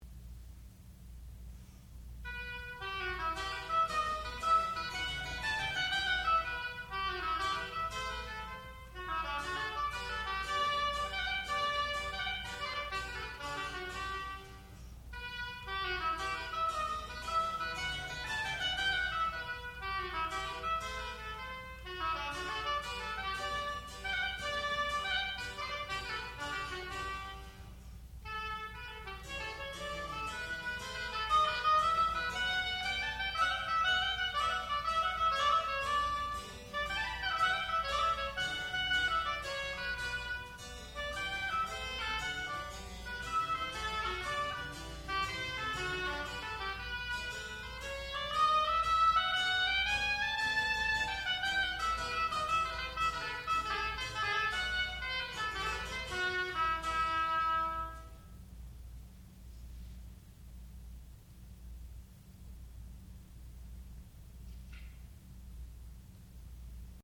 classical music
oboe
harpsichord
Graduate Recital